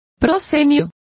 Complete with pronunciation of the translation of apron.